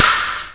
Shot1
SHOT1.WAV